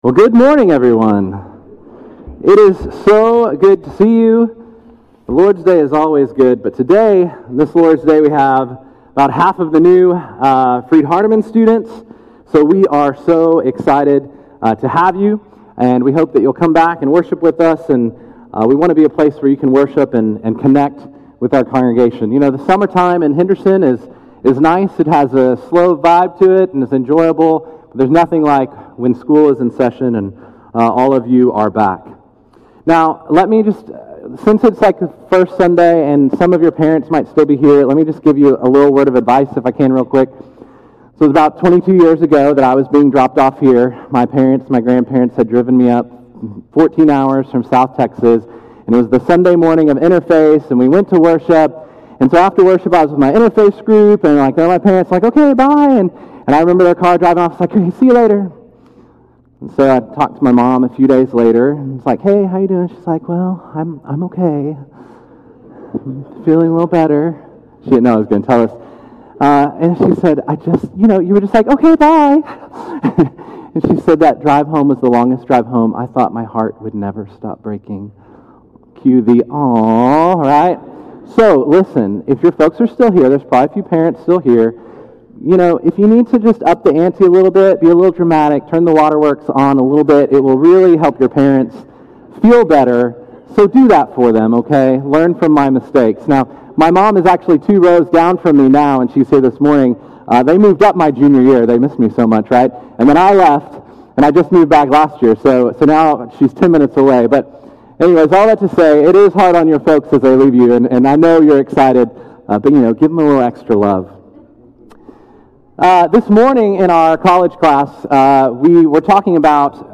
Answering the Call – Henderson, TN Church of Christ